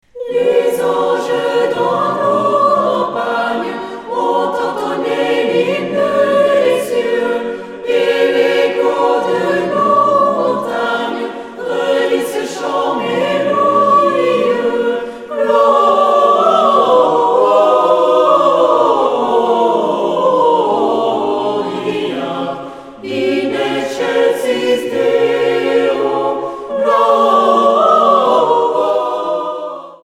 Chants de Noël
chantent à 4 voix